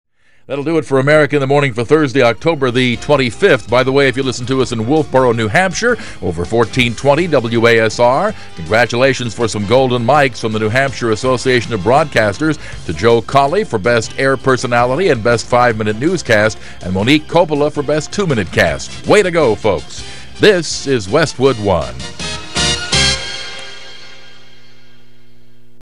national radio personality, Jim Bohanan signed off on the nationally synticated radio talk show America in the MORNING.